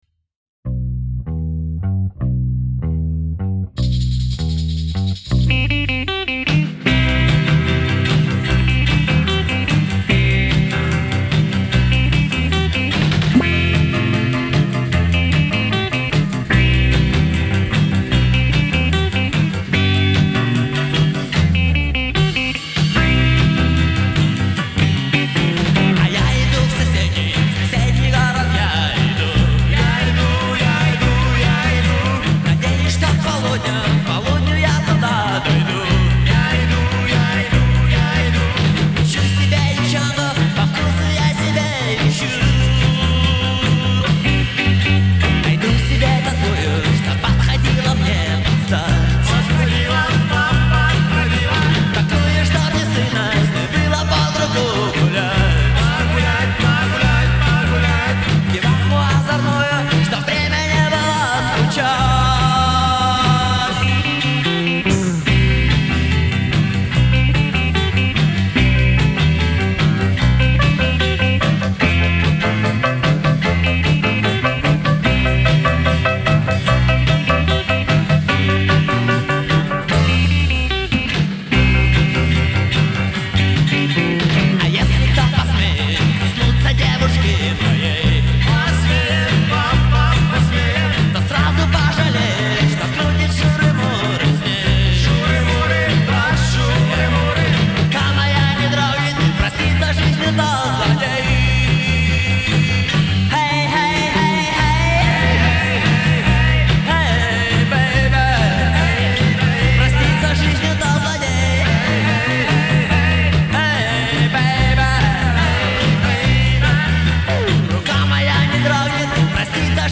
Альбом записан летом 1991 года в Хабаровском Горводоканале
клавишные
барабаны
гитара, вокал
губная гармошка, тамбурин